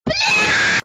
MC kid scream
mc-kid-scream.mp3